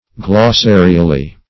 glossarially - definition of glossarially - synonyms, pronunciation, spelling from Free Dictionary Search Result for " glossarially" : The Collaborative International Dictionary of English v.0.48: Glossarially \Glos*sa"ri*al*ly\, adv.